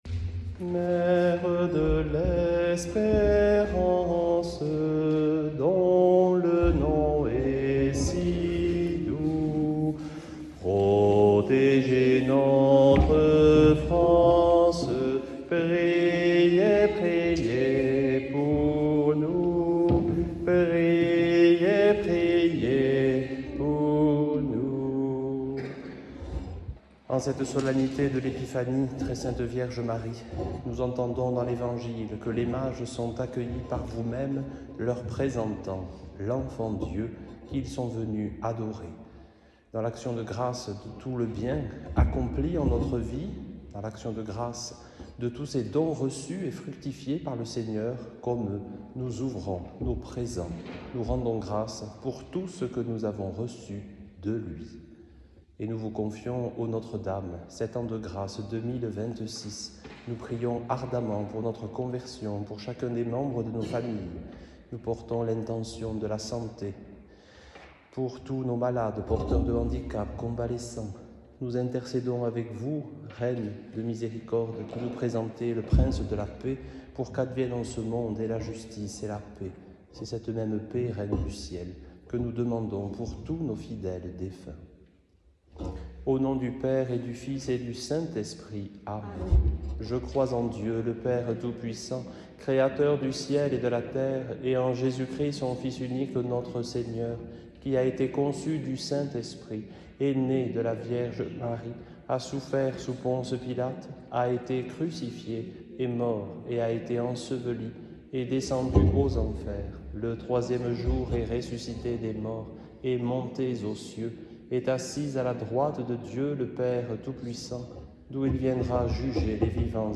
Chapelet dominical - Vox in deserto